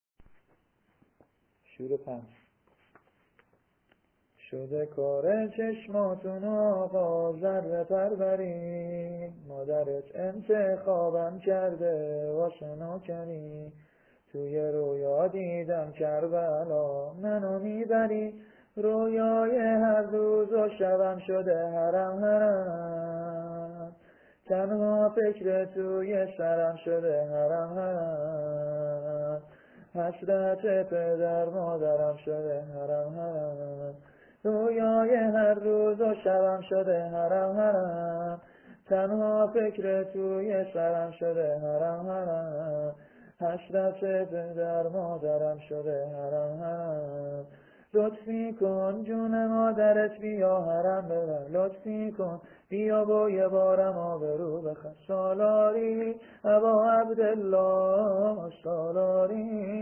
shoor-005.mp3